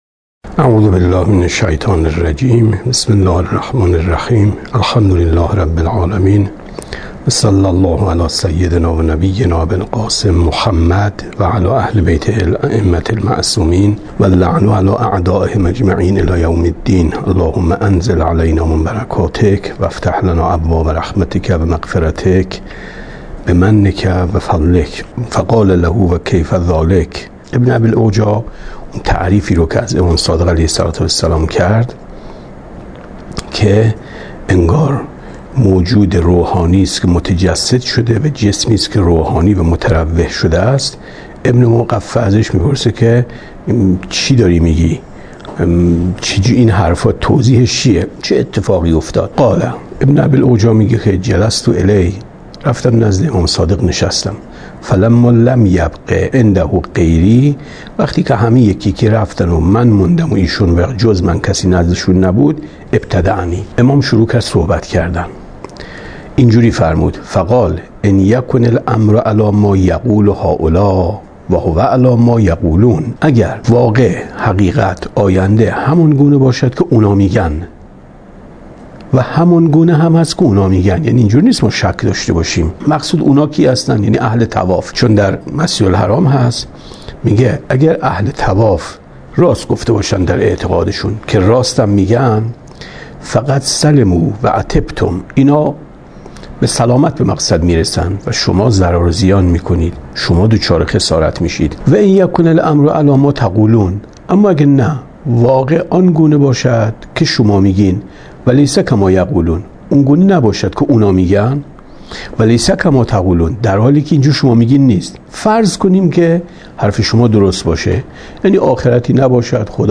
کتاب توحید ـ درس 8 ـ 8/ 5/ 95 شرح اصول کافی درس 126 خدای متعال، اظهر الاشیاء، اعرف الاشیاء و اول المعارف است ادامه [حدیث 2] فَقَالَ لَهُ: وَ كَيْفَ ذلِكَ ؟